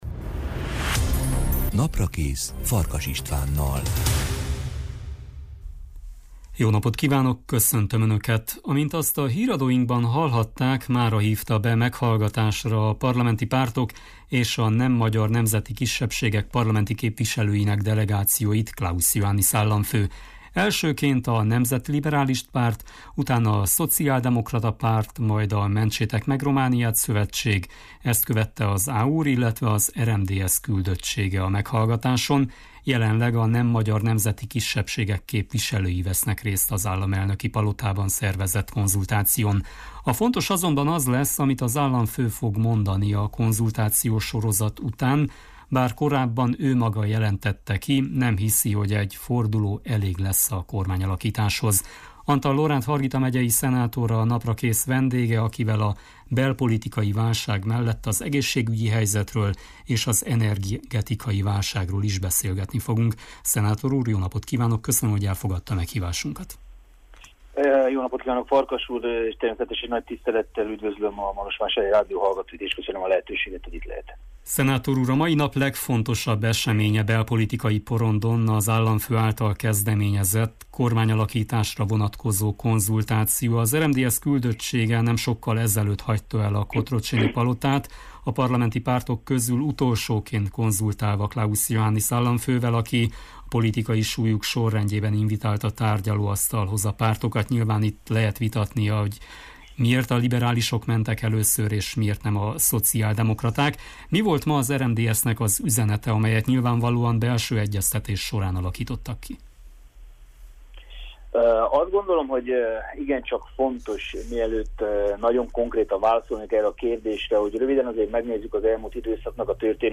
De most a járványgörbe igencsak rossz irányt vett, az energiaárak elszabadultak, a válság pedig tovább mélyül. Antal Lóránt, Hargita megyei szenátor, a felsőház Energetikai és Altalajkincsek Kitermelésével Foglalkozó Bizottságának az elnöke a Naprakész vendége.